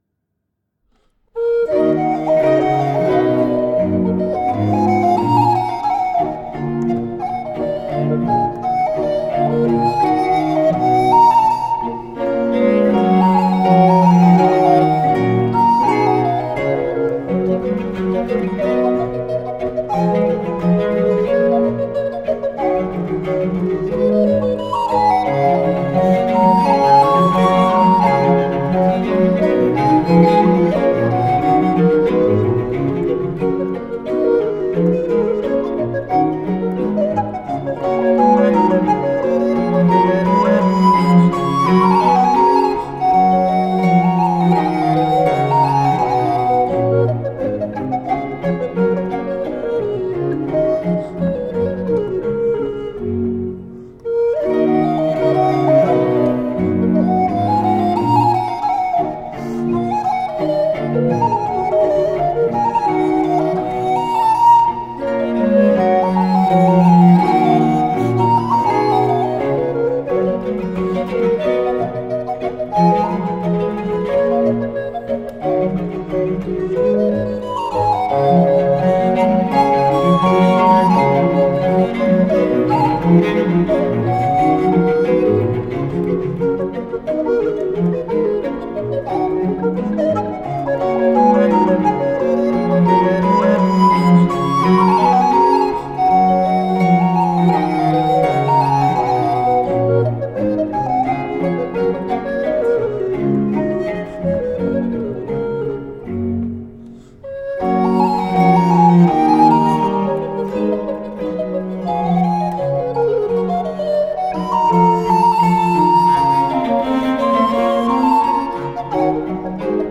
Giuseppe Sammartini (c. 1693-1750): Sonata op. 2 no 3 in E minor. I. Allegro.
recorder
organ
harp
cello.